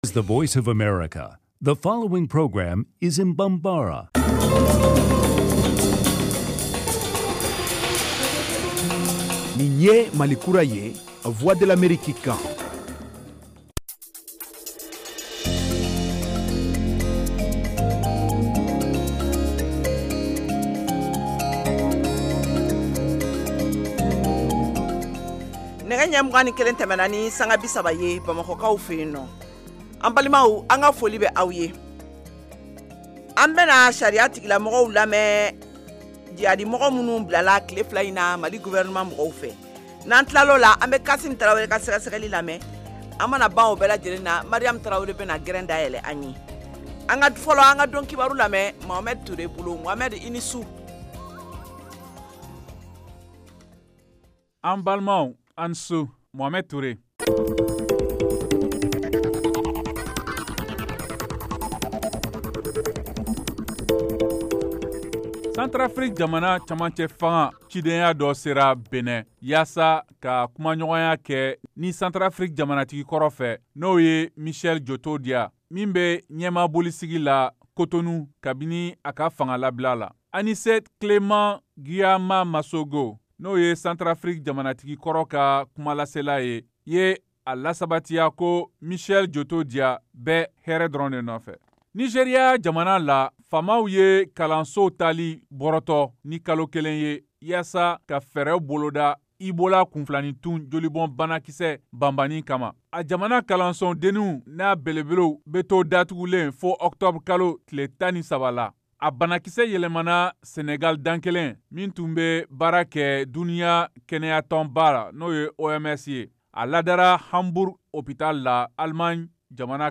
Emission quotidienne en langue bambara
en direct de Washington.